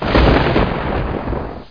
1 channel
thund.mp3